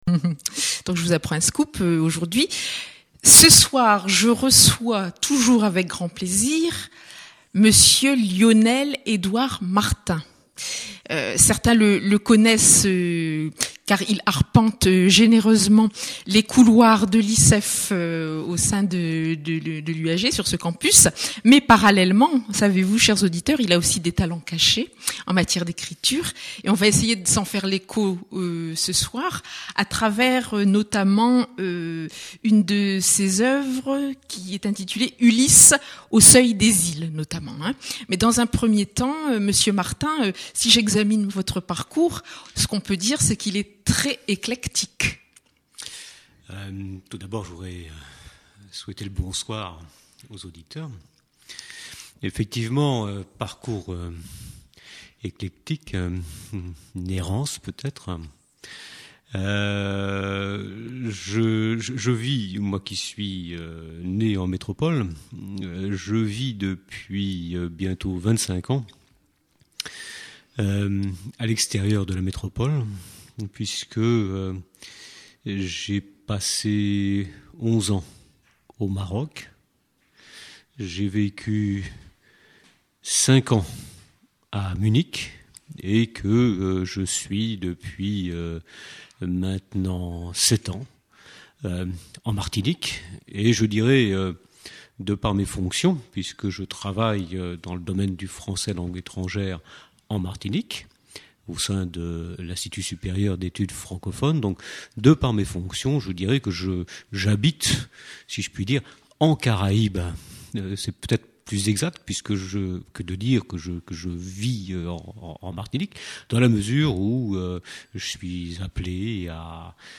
Créolettres : entretien
Émission radiophonique